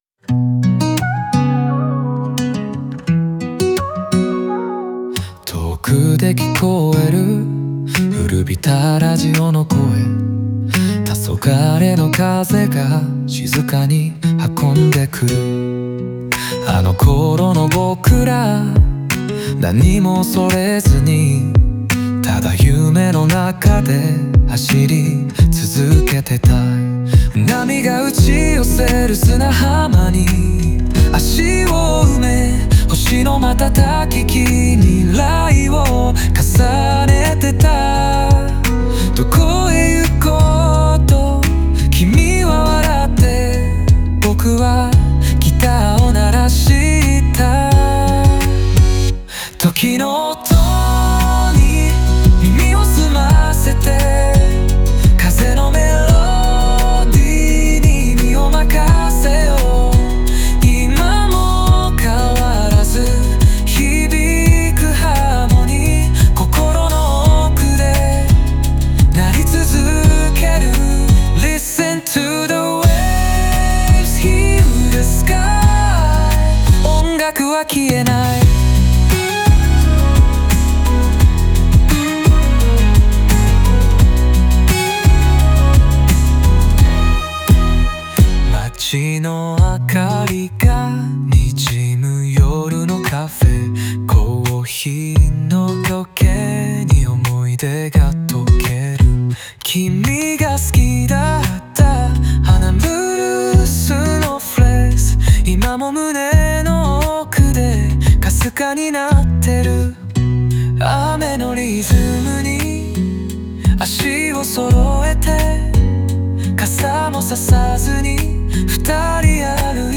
オリジナル曲♪
時間が流れても、旋律とともに蘇る感情を描いたノスタルジックな一曲です。